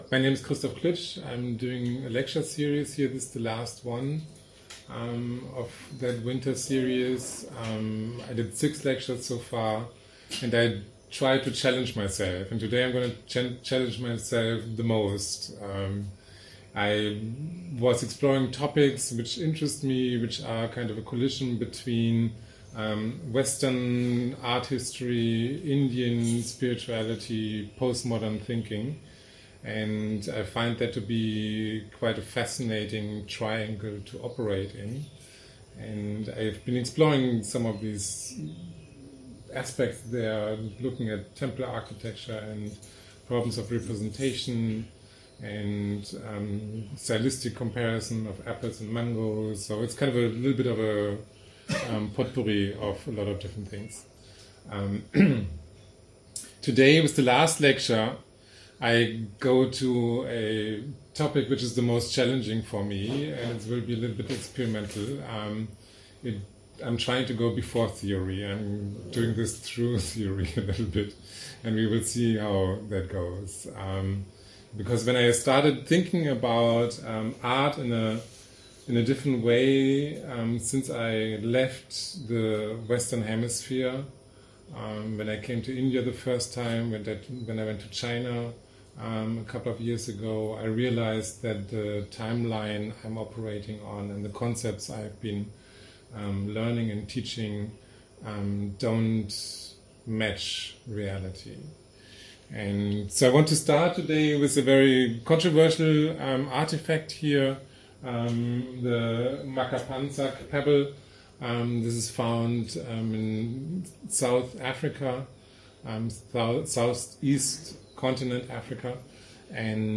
This lecture is the final one in my winter series.